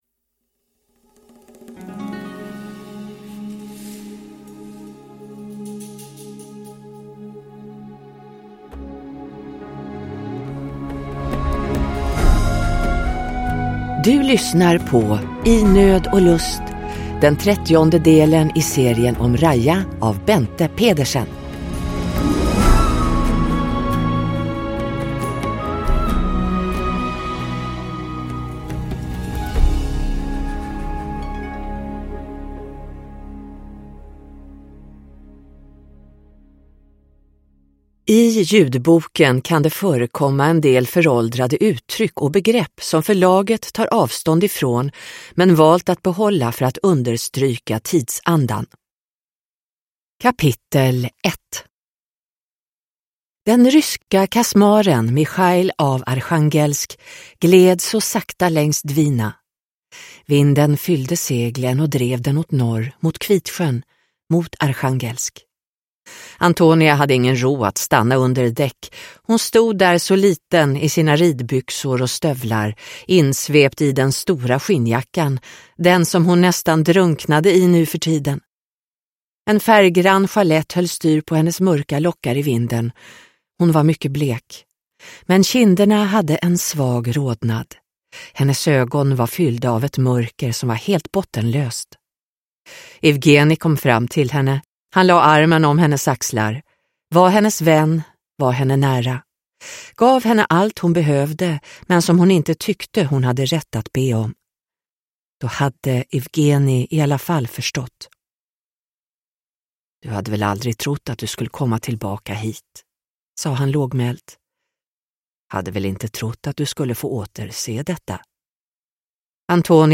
I nöd och lust – Ljudbok – Laddas ner